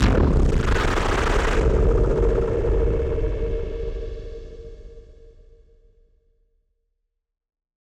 BF_SynthBomb_C-01.wav